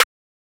pluggsnare.wav